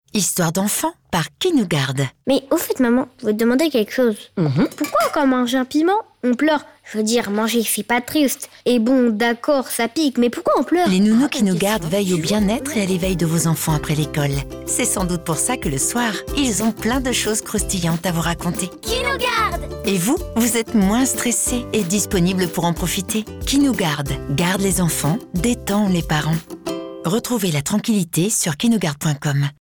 douce